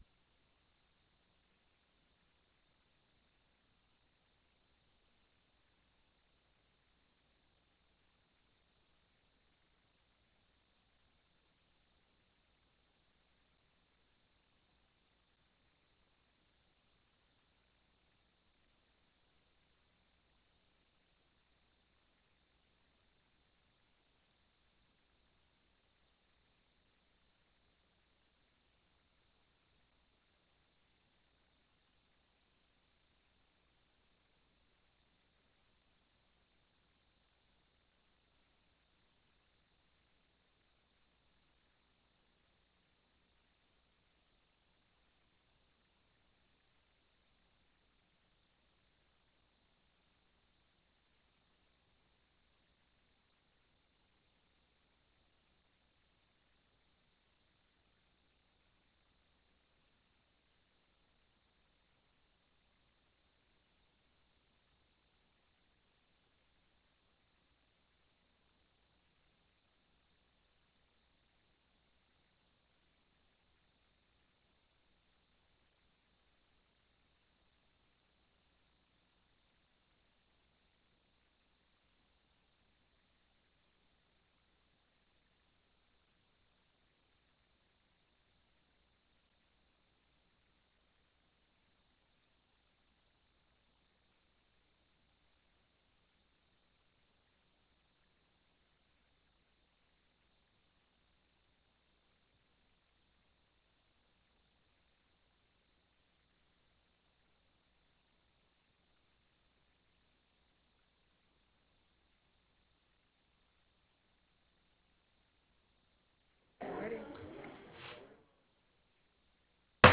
Prairie Room State Capitol Bismarck, ND United States